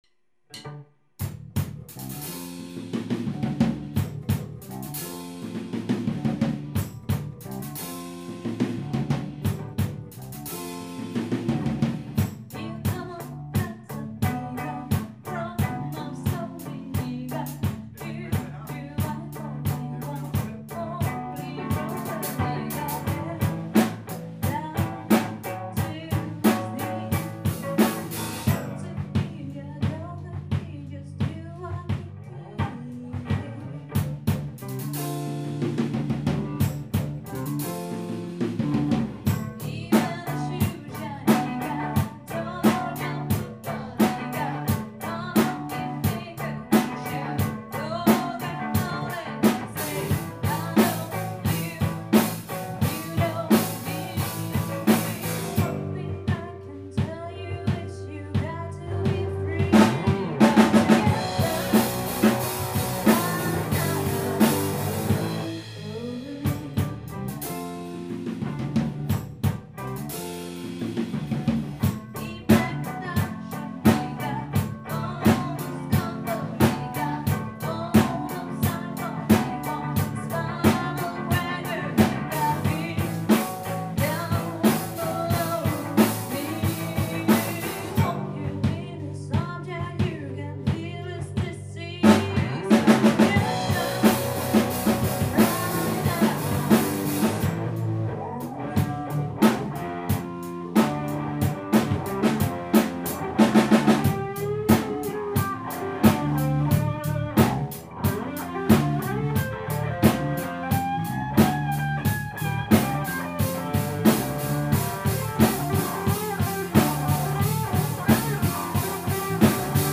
Vi består af Kvindelig forsanger, bas, guitar og trommer.
• Allround Partyband
• Coverband